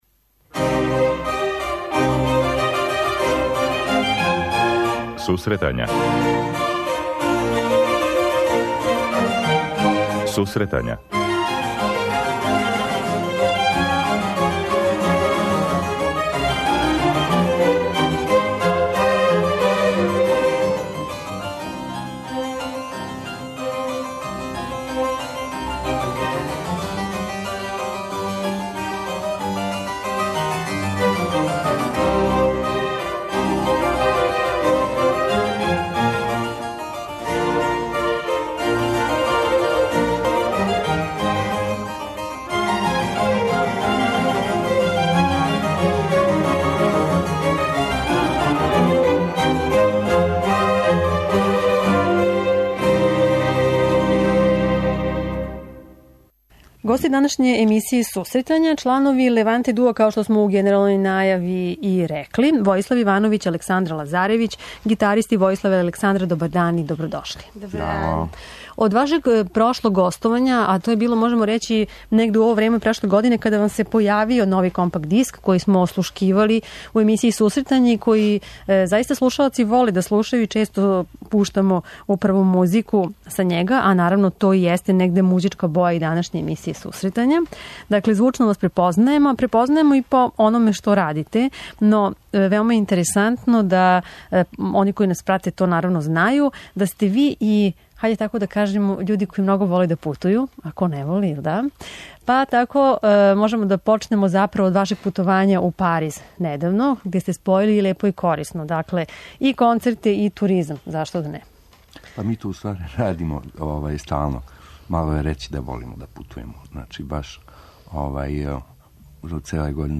преузми : 26.22 MB Сусретања Autor: Музичка редакција Емисија за оне који воле уметничку музику.